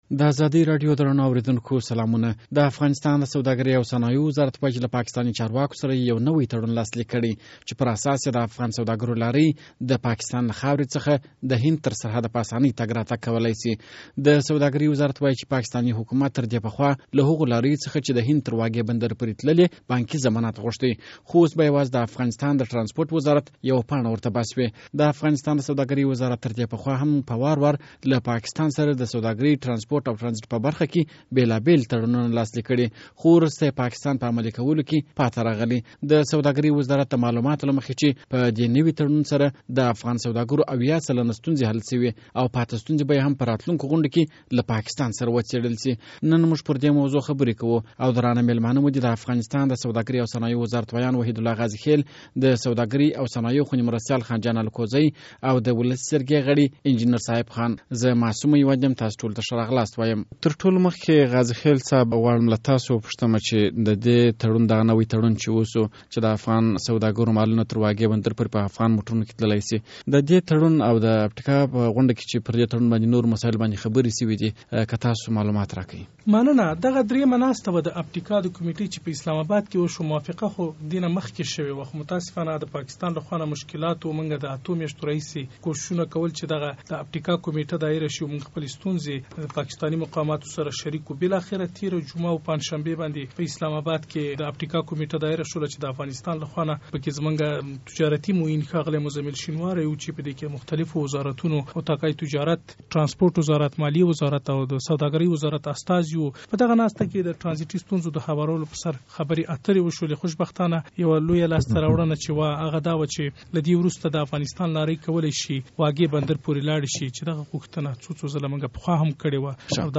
د ازادۍ راډیو د اوونۍ بحث